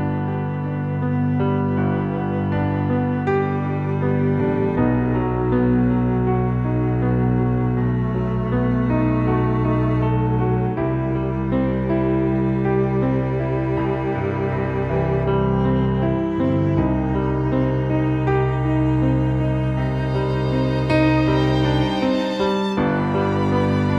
Original Male Key